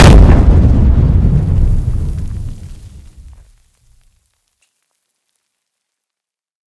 large-explosion-3.ogg